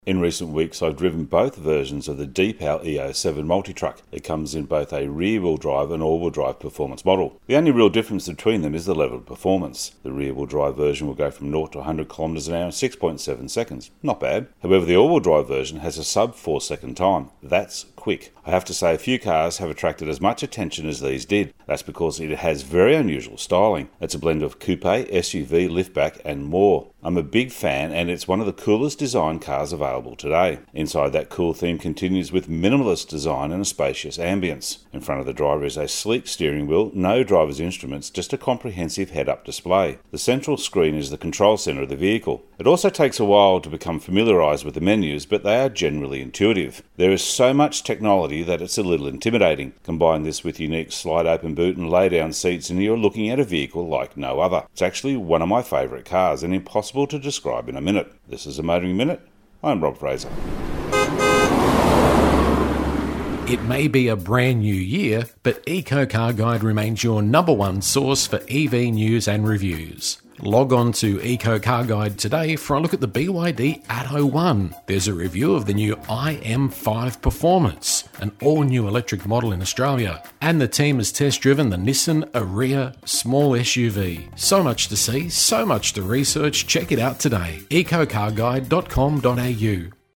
Motoring Minute is heard around Australia every day on over 120 radio channels.